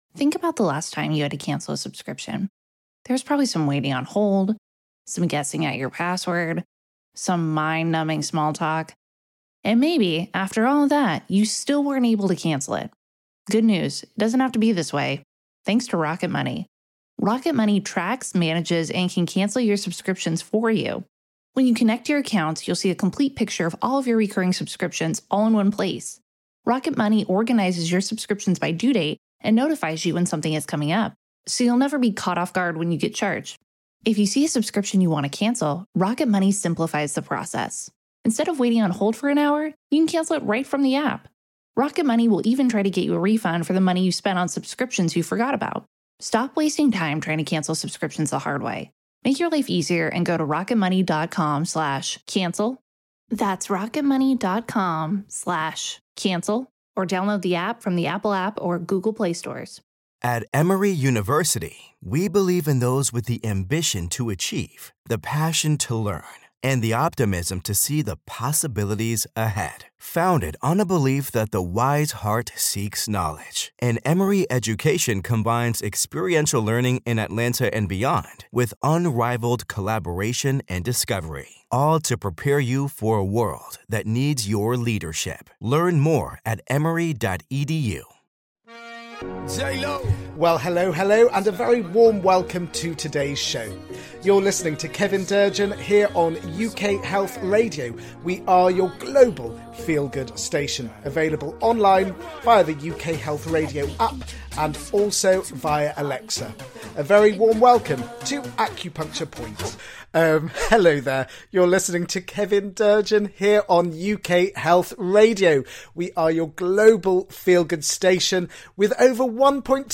He will interview a wide range of people from practitioners to policy makers and will also provide health related updates particularly in the field of complementary health.
He will also play some gorgeous music to uplift your soul and get your feet tapping with happiness.